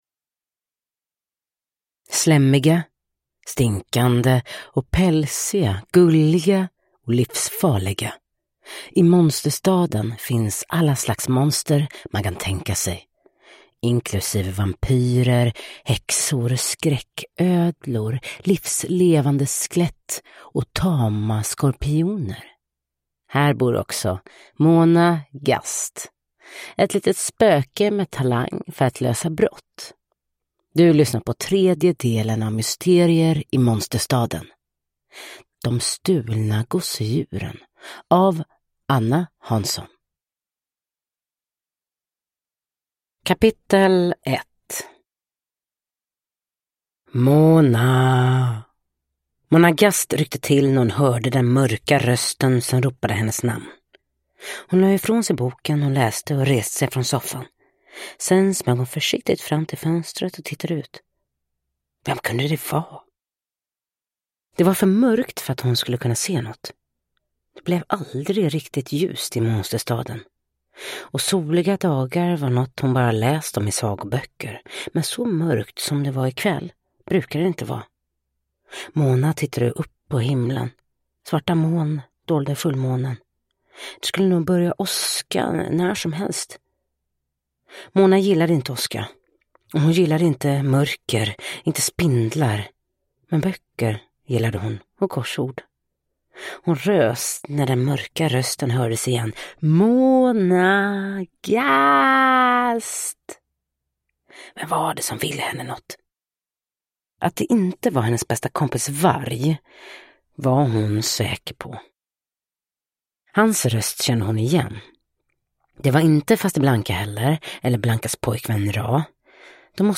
De stulna gosedjuren – Ljudbok